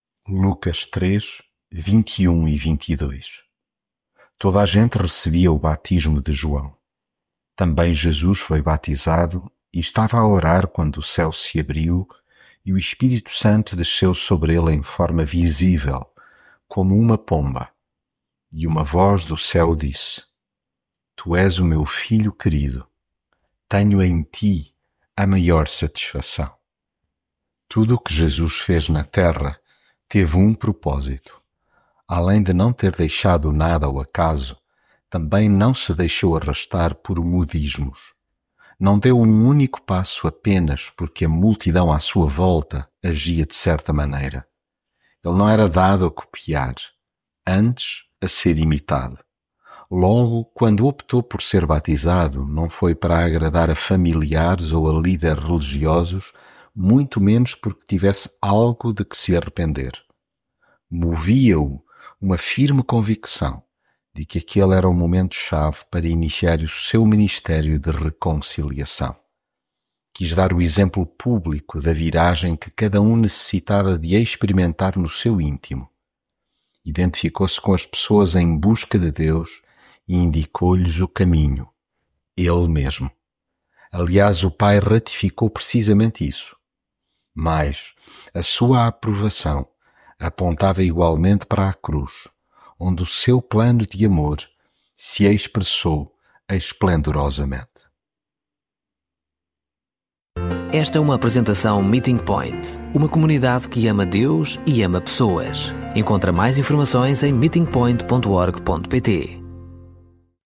devocional Lucas leitura bíblica Toda a gente recebia o batismo e Jesus também foi batizado.